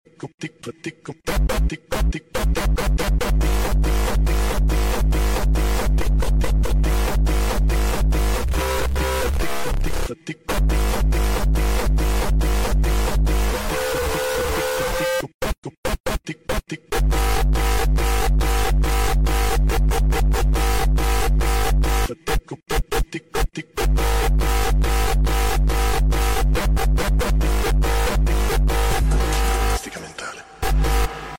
This man is taking over the uptempo hardcore scene!!